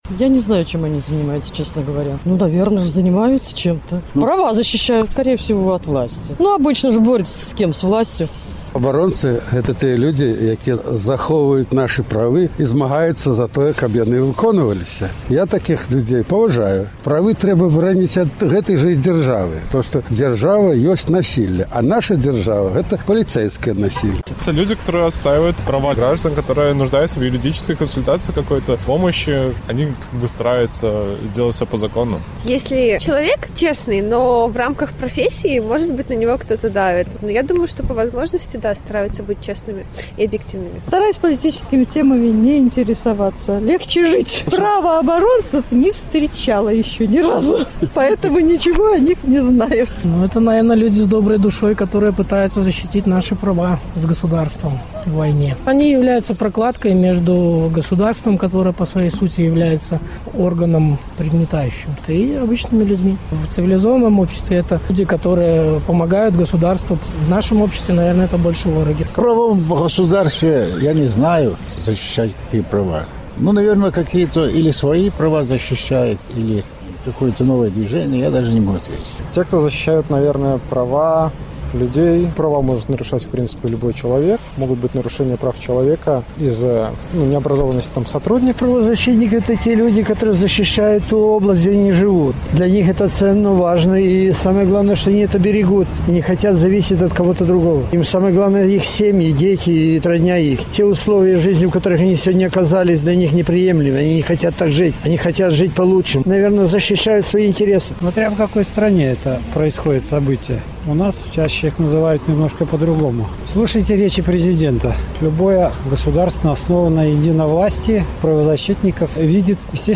Гэтыя ды іншыя пытаньні ў нашай праграме абмяркоўваюць людзі, якія ў той або іншай ступені займаюцца праваабарончай дзейнасьцю, а таксама мінакі на магілёўскіх вуліцах.
Апытаньне: Чым займаюцца праваабаронцы?